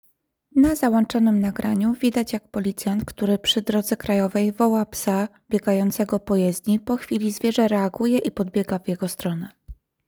Nagranie audio Audiodeskrypcja do filmu